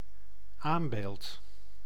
Ääntäminen
Vaihtoehtoiset kirjoitusmuodot aanbeeld Ääntäminen Tuntematon aksentti: IPA: /ˈaːmˌbeːlt/ Haettu sana löytyi näillä lähdekielillä: hollanti Käännös Konteksti Substantiivit 1. anvil ihmisen luuranko Suku: n .